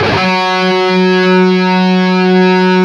LEAD F#2 LP.wav